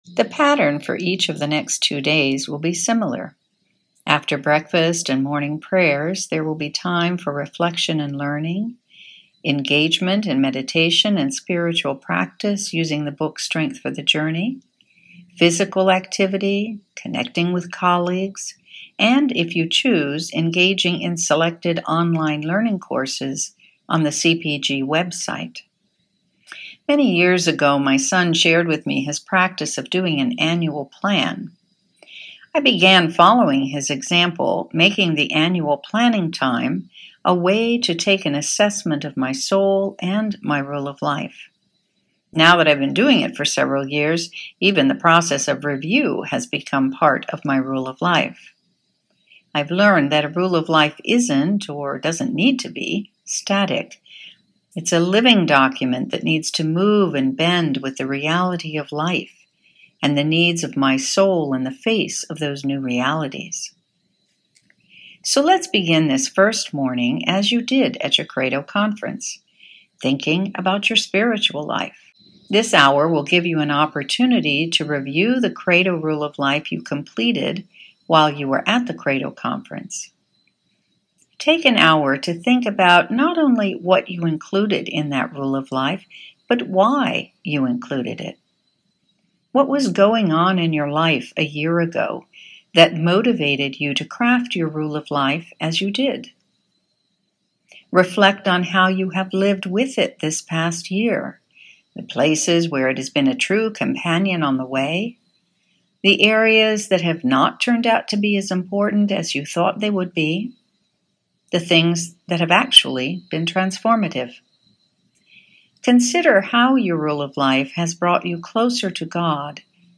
annual-retreat---1st-hour-meditation-1.mp3